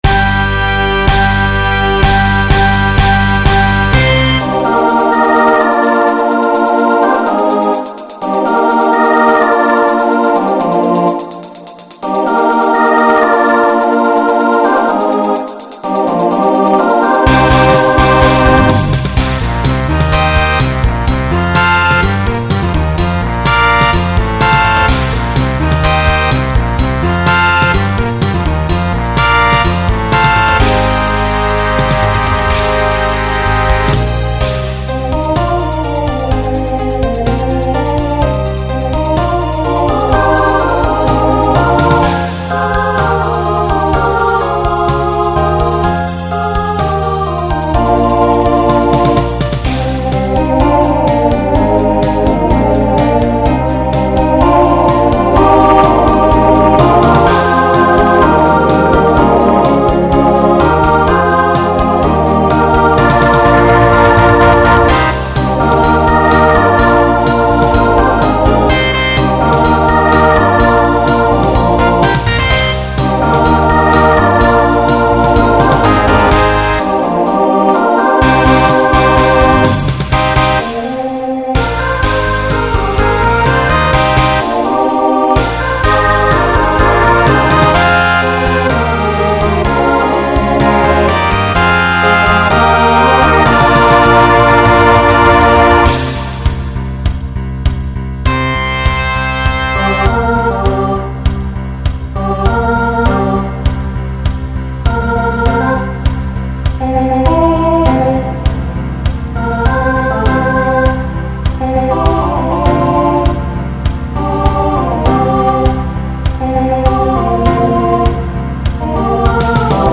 Voicing SATB Instrumental combo Genre Rock
1980s Show Function Mid-tempo